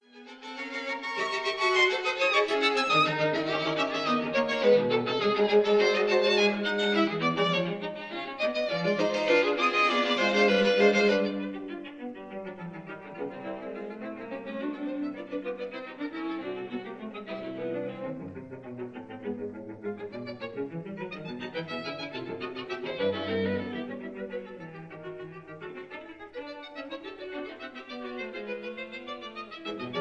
This is a 1950 recording
(Allegro assai mosso)